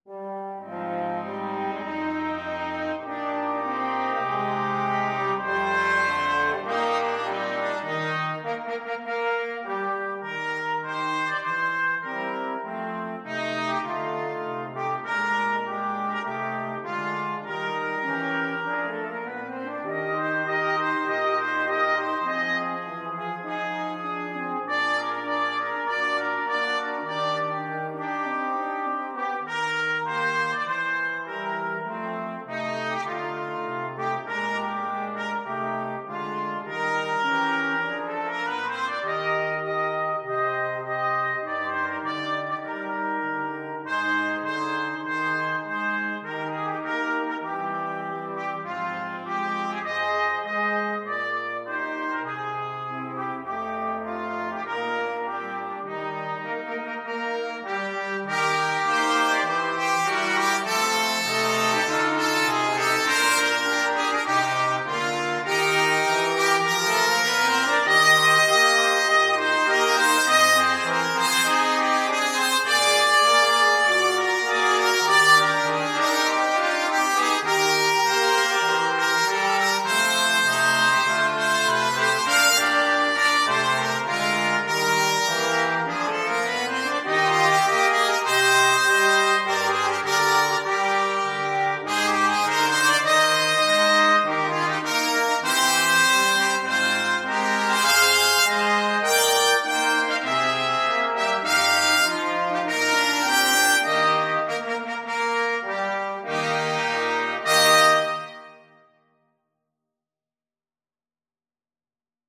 Trumpet 1Trumpet 2French HornTrombone
Moderato = c. 100
4/4 (View more 4/4 Music)
Jazz (View more Jazz Brass Quartet Music)
Rock and pop (View more Rock and pop Brass Quartet Music)